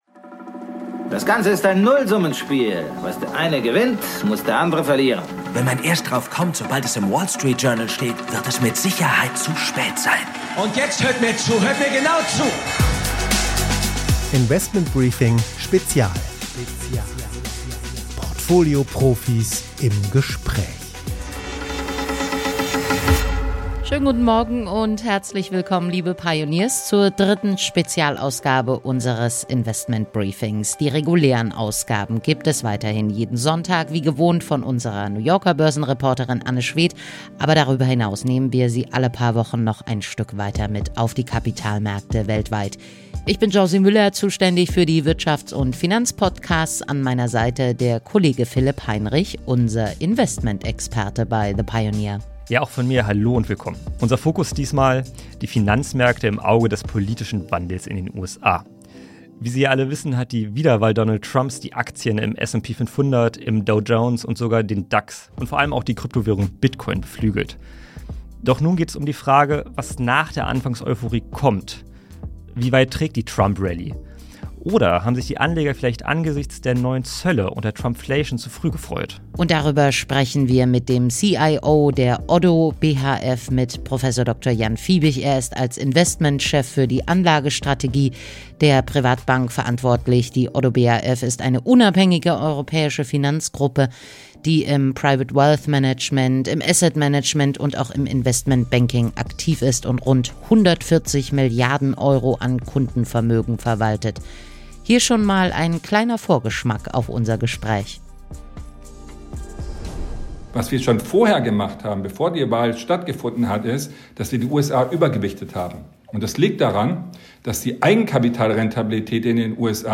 Portfolio-Profis im Gespräch.